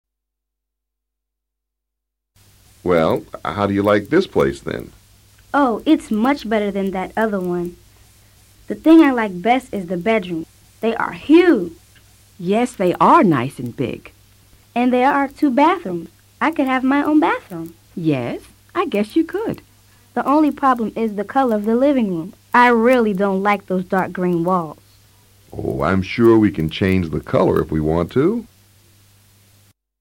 Los Den parecen entusiasmados con este departamento. Escucha con atención y trata de repetir el diálogo luego.